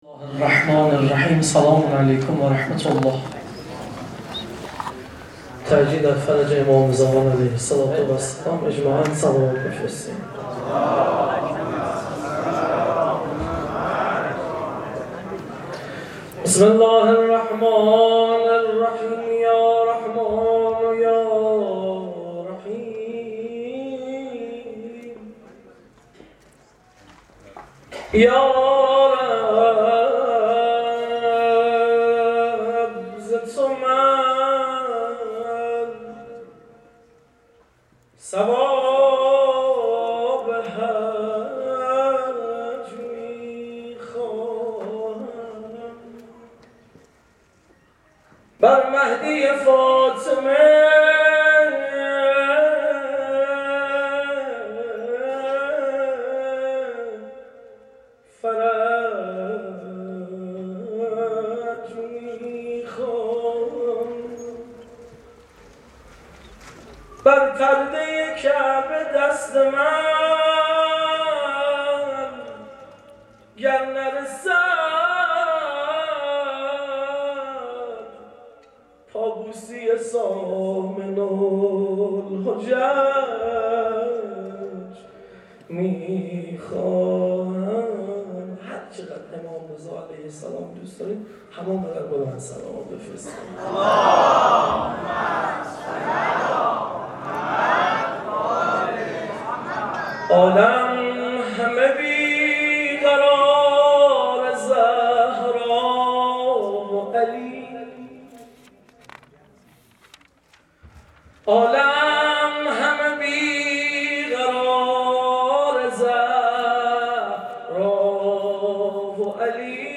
صوت مداحی برگزیدۀ بخش جوانان یازدهمین سوگوارۀ نواها و اشعار آئینی منتشر می‌شود.